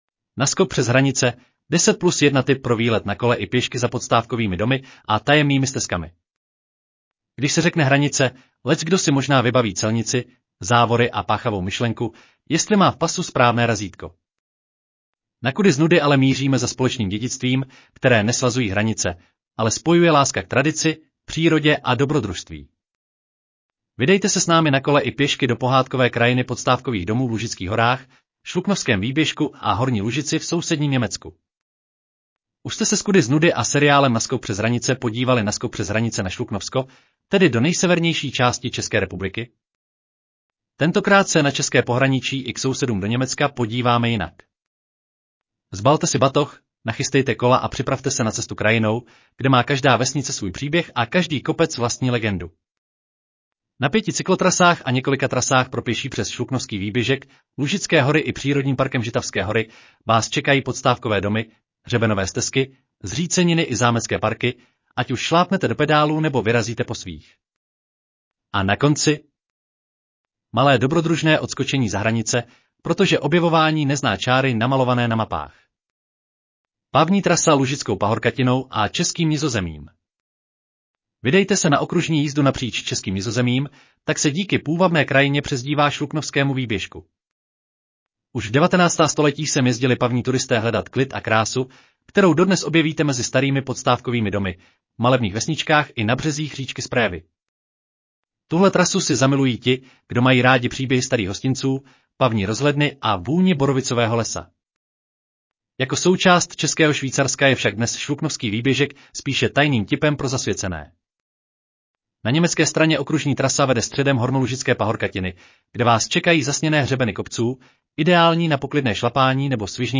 Audio verze článku Na skok přes hranice: 10 + 1 tip pro výlet na kole i pěšky za podstávkovými domy a tajemnými stezkam